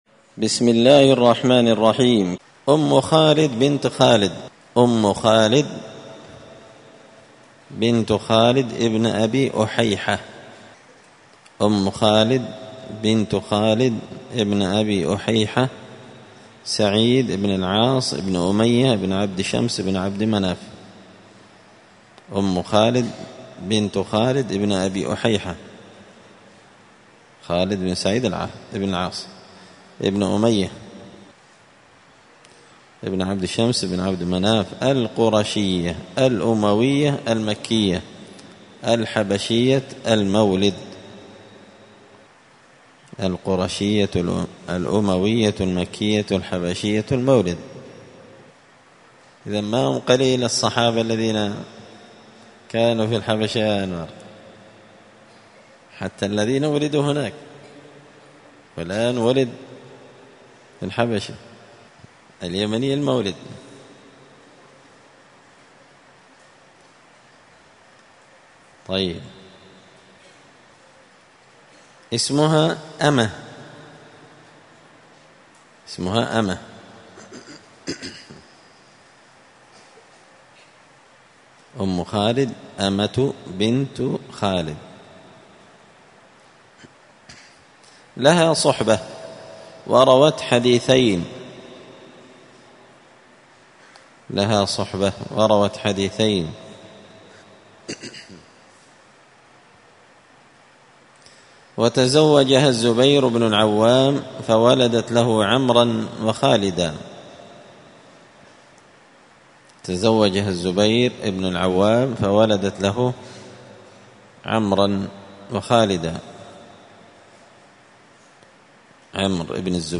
قراءة تراجم من تهذيب سير أعلام النبلاء
مسجد الفرقان قشن المهرة اليمن